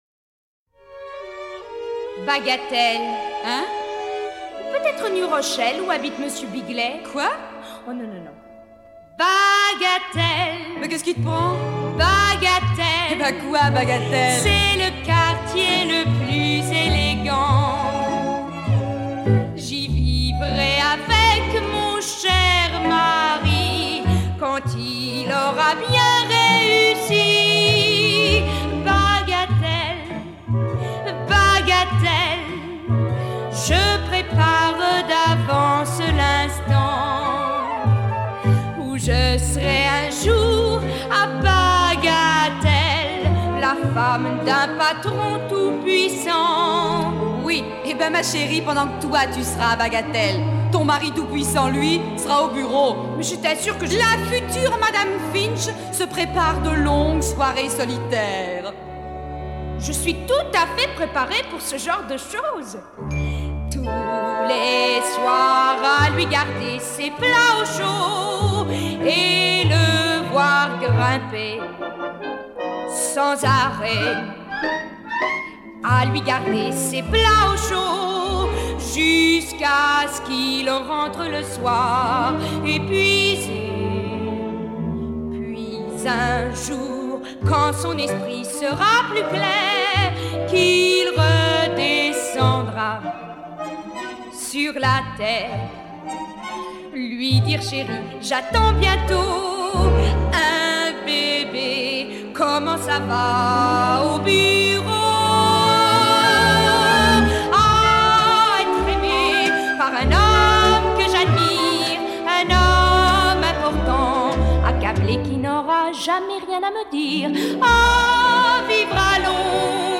Original French Cast Recording